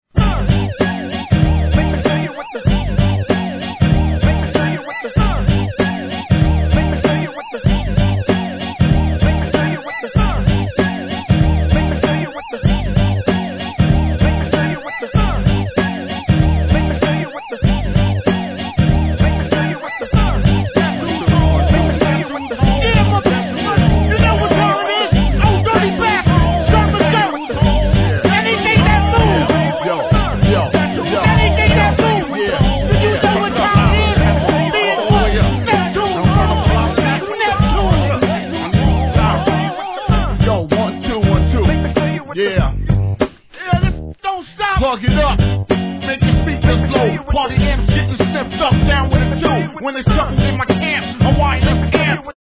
HIP HOP/R&B